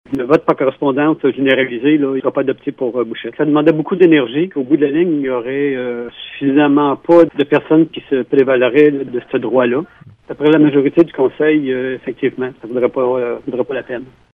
Gilles Bastien, maire de Bouchette, estime que peu d’électeurs auraient opté pour le vote par correspondance.